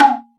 PERC - PUP.wav